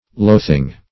Loathing \Loath"ing\, n.